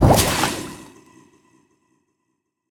25w18a / assets / minecraft / sounds / mob / husk / convert1.ogg